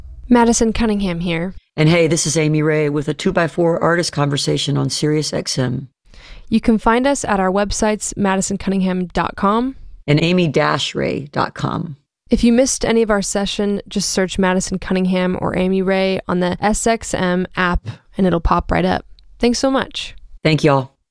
(audio capture from web stream)
20. conversation (amy ray and madison cunningham) (0:21)